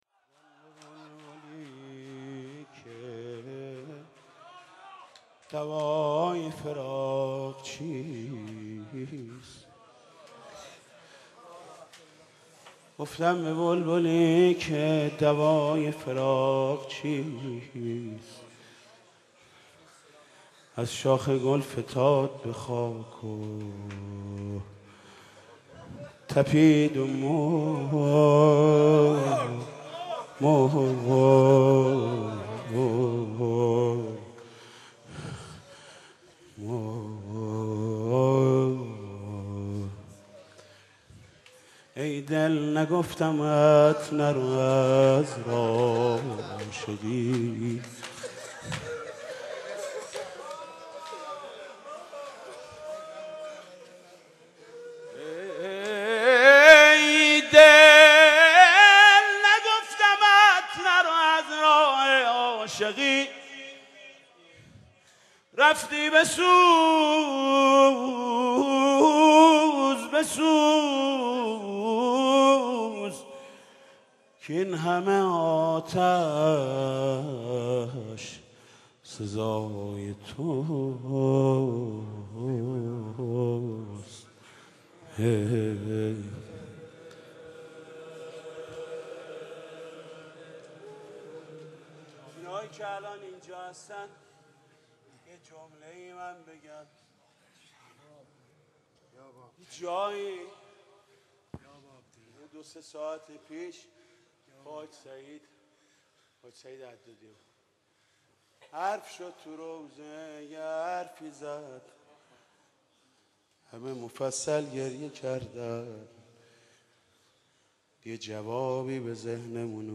مناسبت : اربعین حسینی
مداح : محمود کریمی